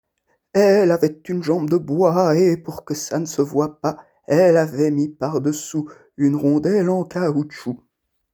Type : chanson narrative ou de divertissement | Date : 6 mars 2026
Mode d'expression : chant